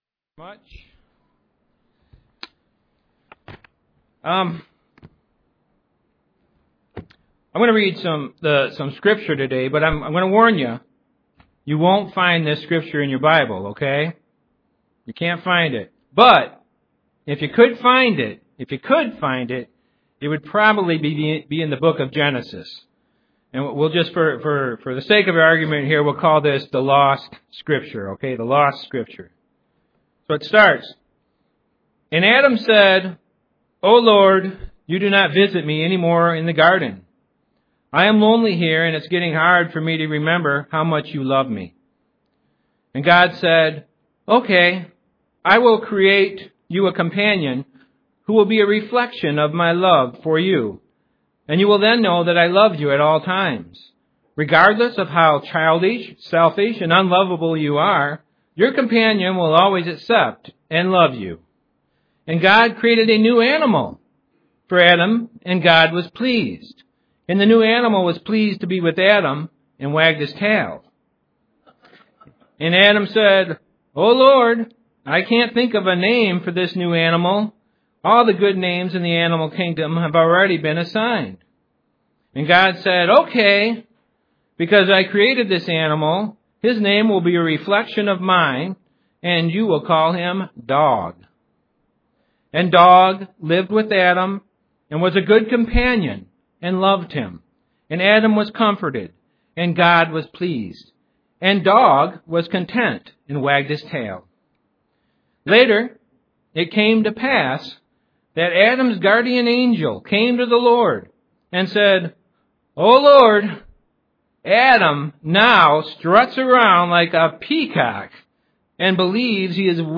UCG Sermon Studying the bible?
Given in Grand Rapids, MI